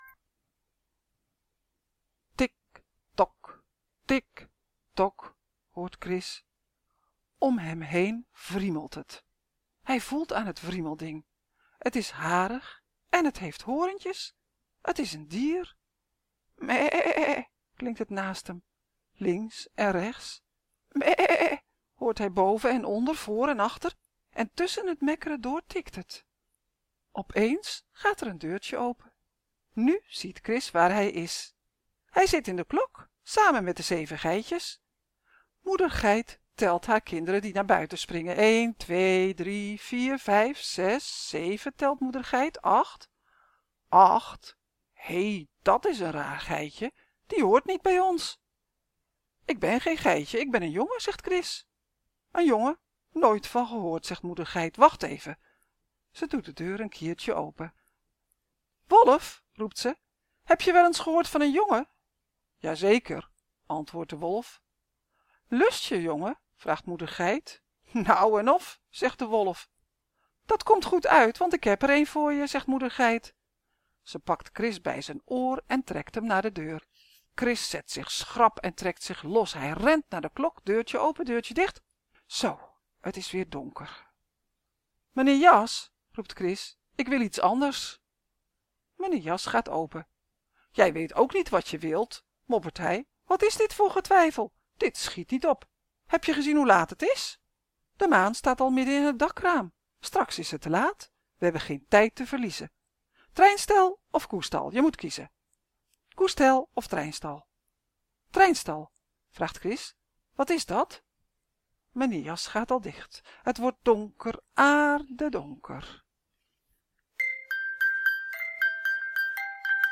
BESTEL het luisterboek Dappere Chris op de enge zolder 3,99 €